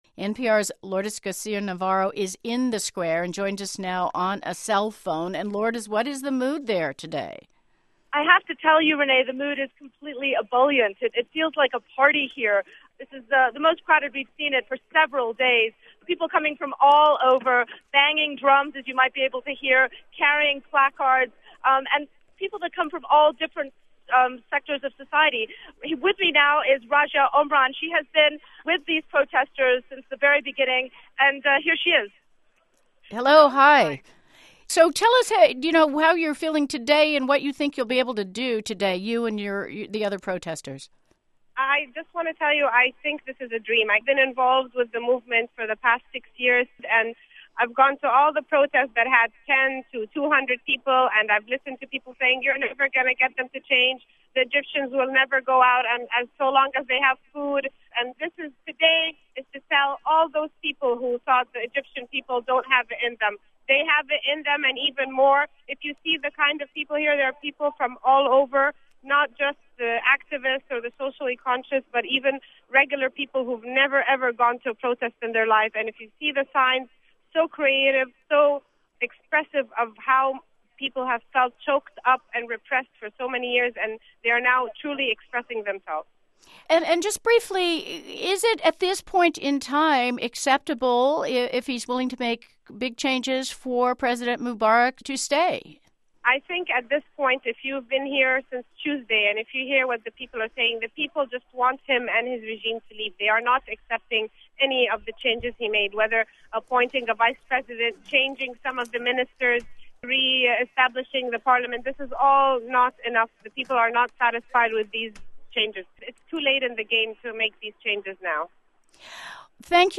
Anti-Mubarak demonstrators were aiming to bring 1 million people into the streets — and might have drawn even more. The mood among many is "ebullient," NPR's Lourdes Garcia-Navarro reports from Tahrir Square in Cairo.